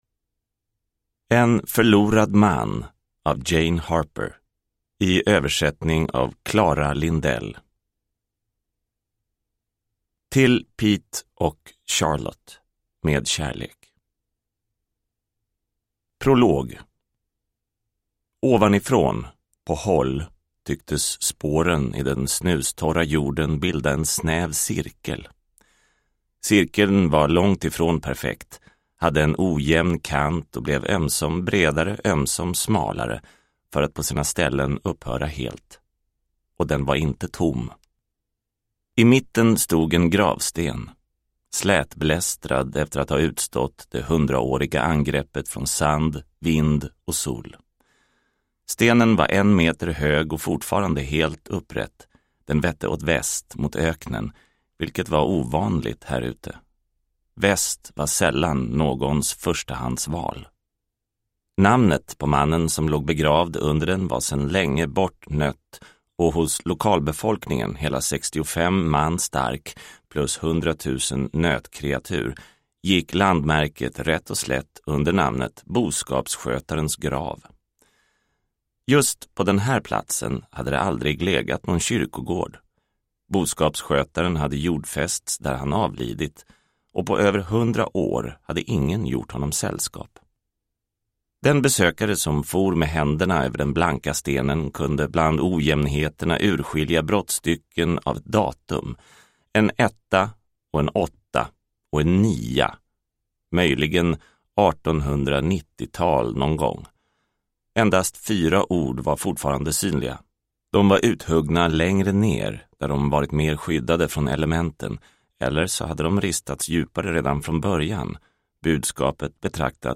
En förlorad man – Ljudbok – Laddas ner